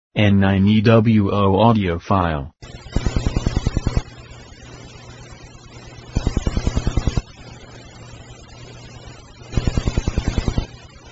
When the Wi-Fi is switched on we detected a pulsing type of interference that irked into test sample audio amplifier
This was most noticeable with strong MW / SW broadcast stations with quiet audio.
ats25_4.1_wi-fi_rfi.MP3